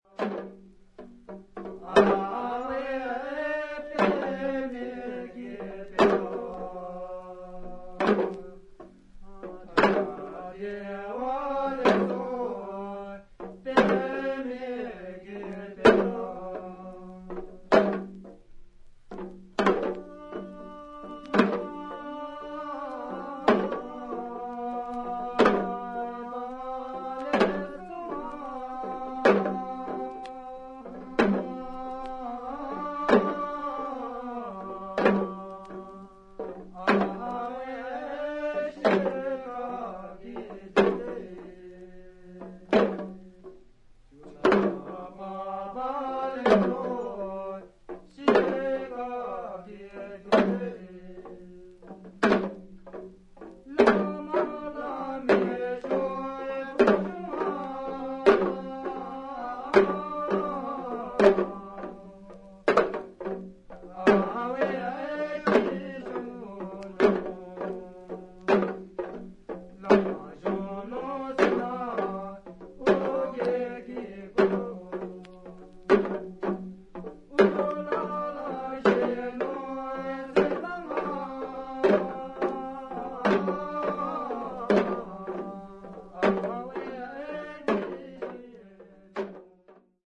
Two Folk-songs